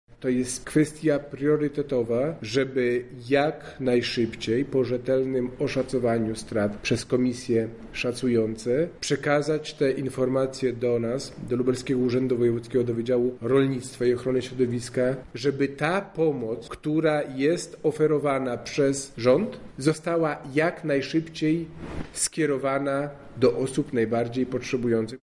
O sprawie mówi Robert Gmitruczuk, Wicewojewoda Lubelski: